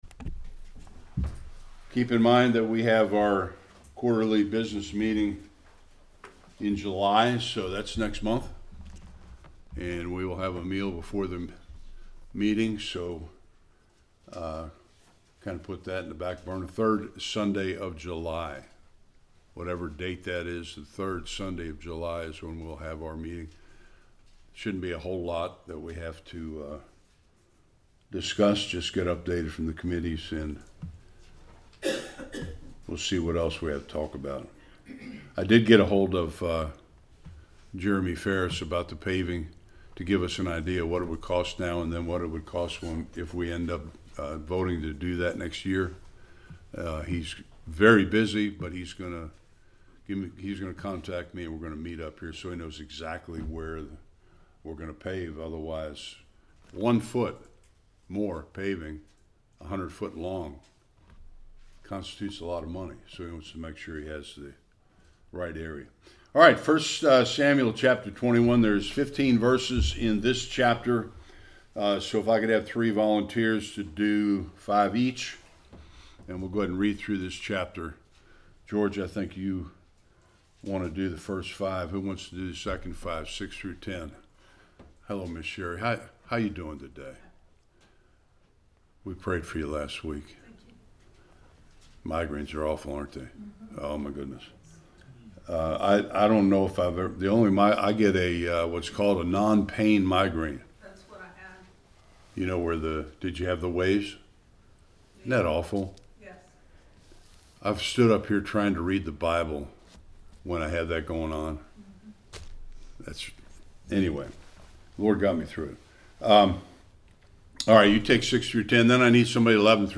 1-5 Service Type: Sunday School David begins a 10-year exile from King Saul.